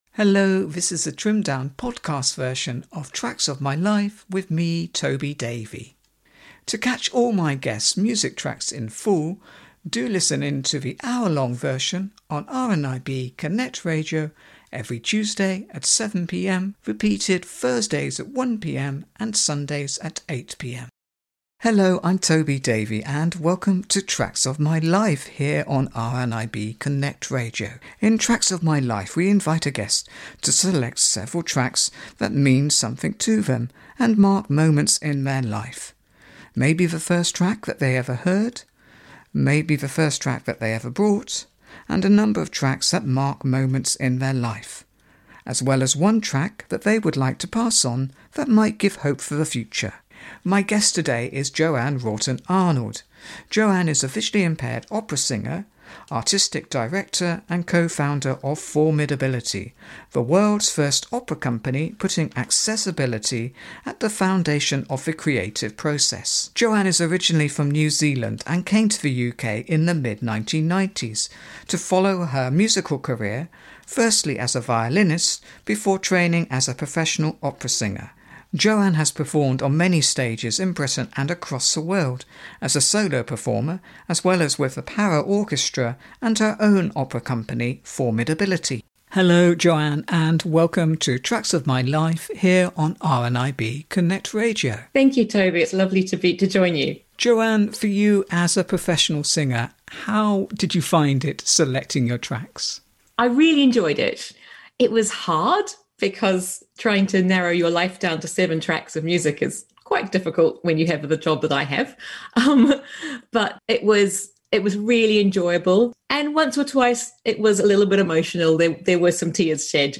Tracks of My Life is a show on RNIB Connect Radio